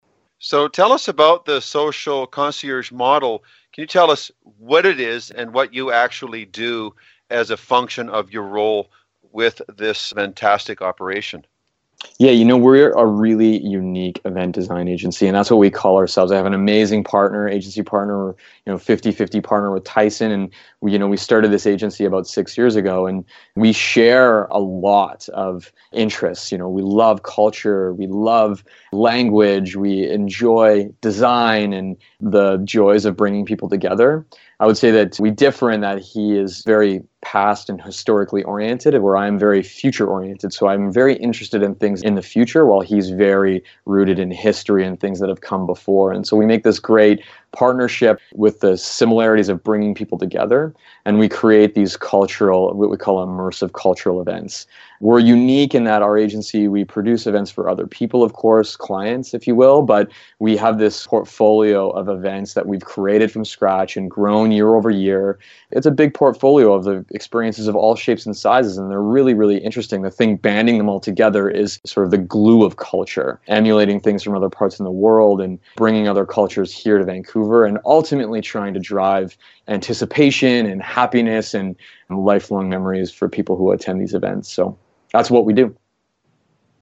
Special Guest Interview Volume 16 Number 1 V16N1c